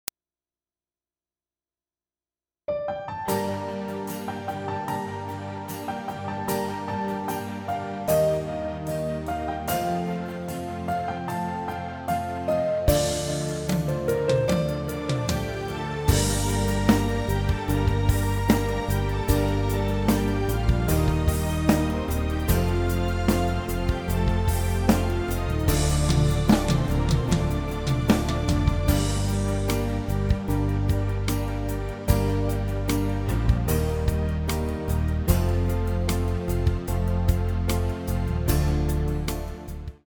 פלייבק איכותי – תואם מקור